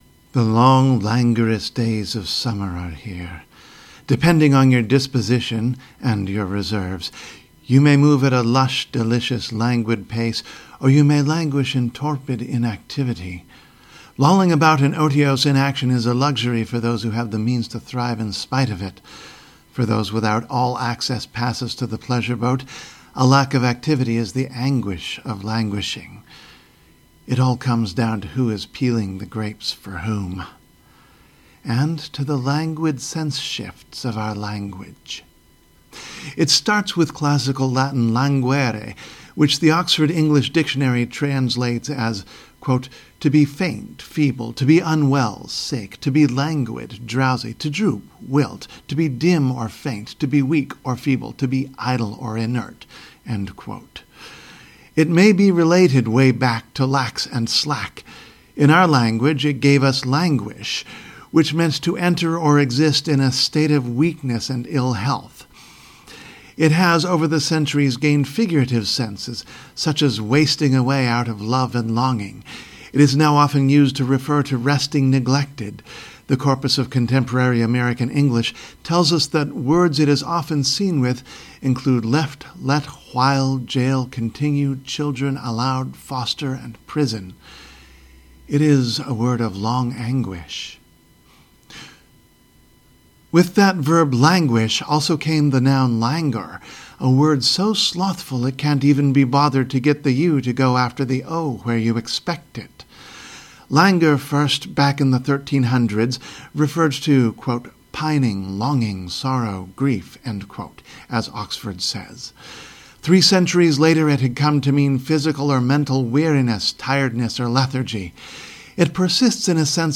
Reading: languor, languid, languish